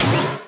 girl_charge_1.wav